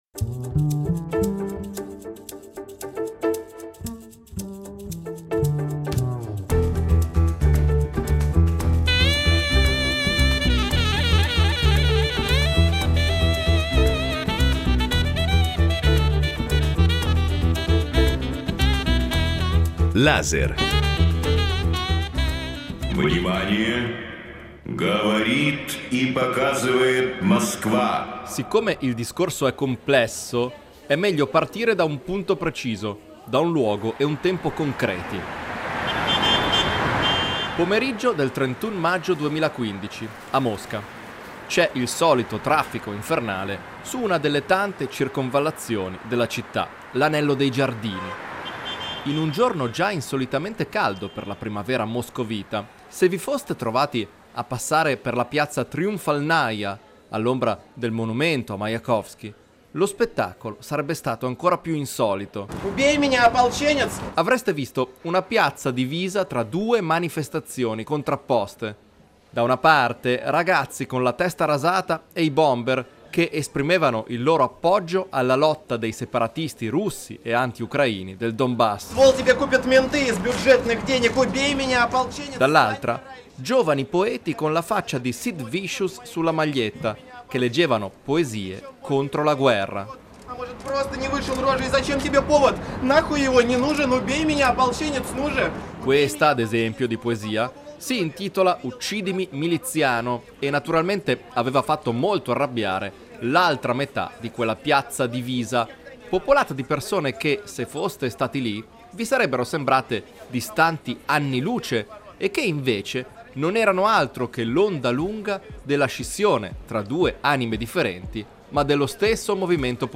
Un'intervista allo scrittore russo e un tentativo di raccontare la sua attività politica,